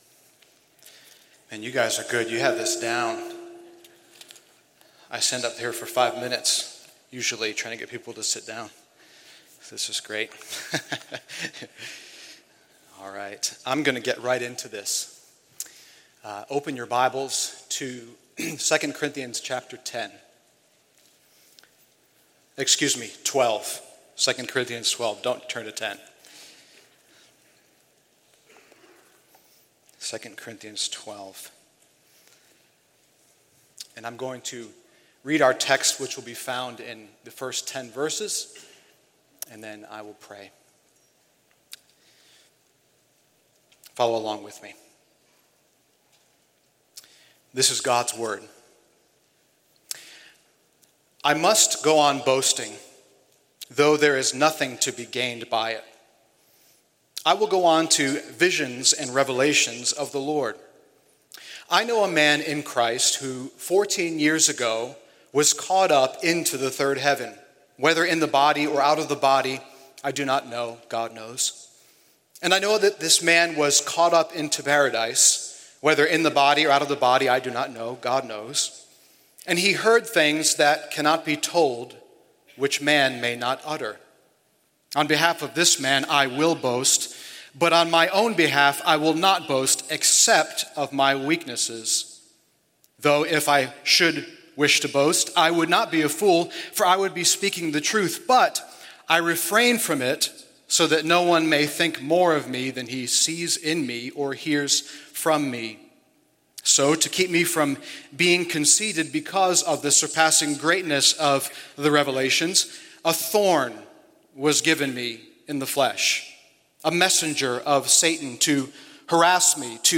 A message from the series "Guest Speakers."